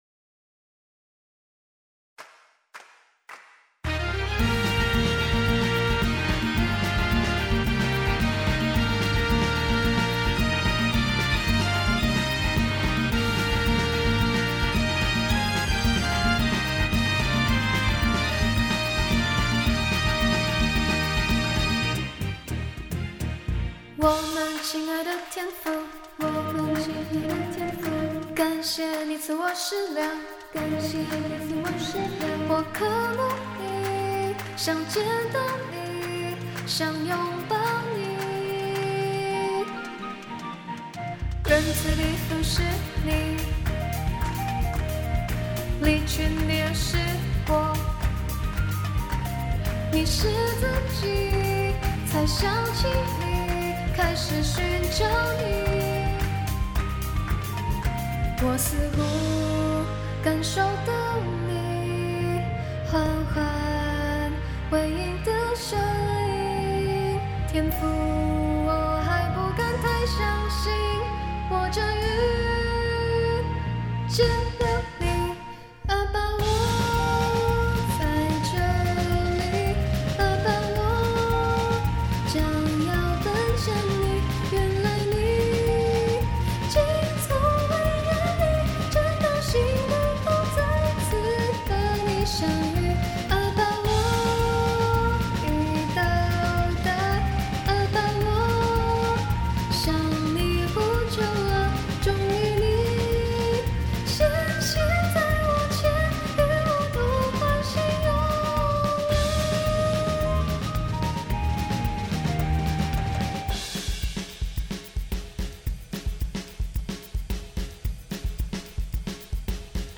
此曲為降 E 大调，Samba 风格；曲子的开始就富有节奏性，管弦乐把气氛欢 乐起来。到了主歌部分，木笛陪伴著歌手的歌声，在后面模仿旋律；木笛表示圣神都陪著在我 们身边。
到了曲子的 bridge，热闹的打击乐忽然消失；曲子变 得比较柔顺，彷彿自己要独自告诉天主我们自己心裡的话语。到了 final chorus 的后半段，曲 子开始热闹起来，开开心心的结束。